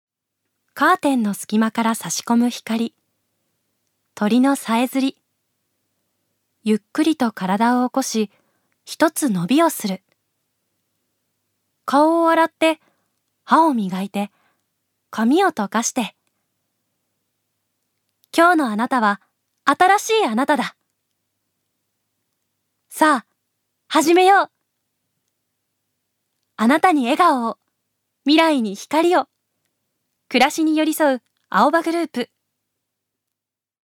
預かり：女性
ナレーション３